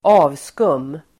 Uttal: [²'a:vskum:]